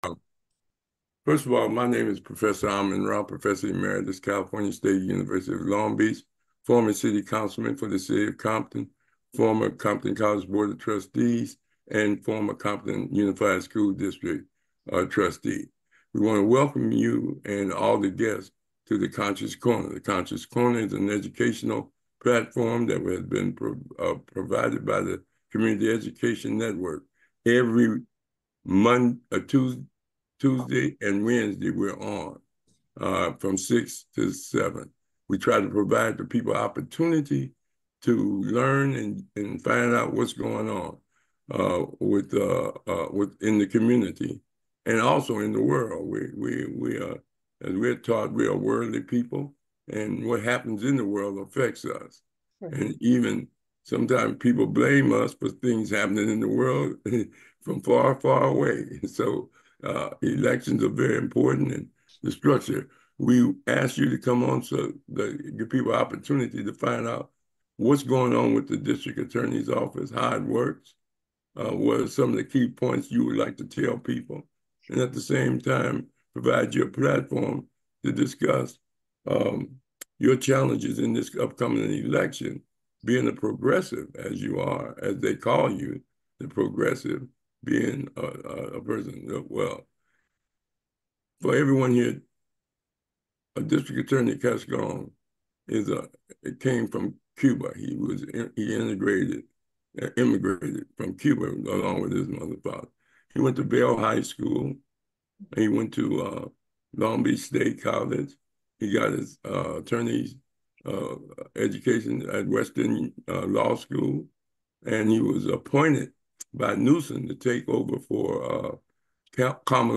Conscious Corner - A Discussion with George Gascón